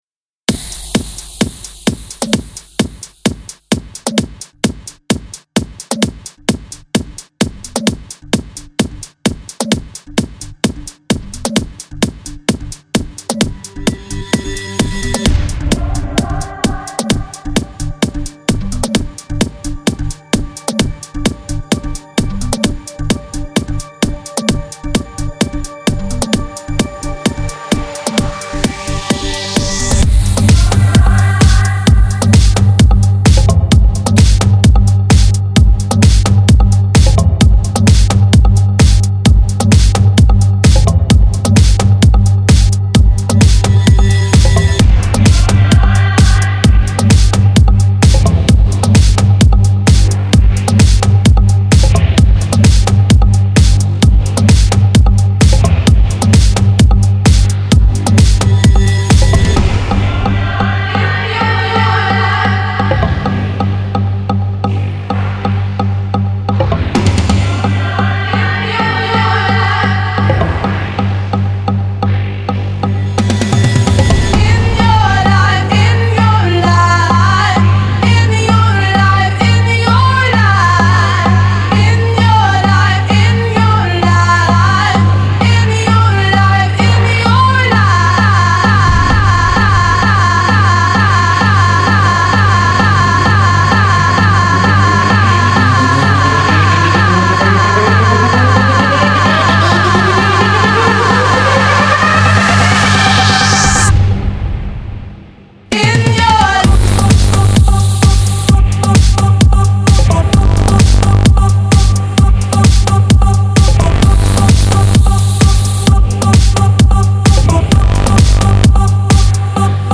Genre : House